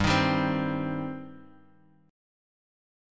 Gsus4#5 chord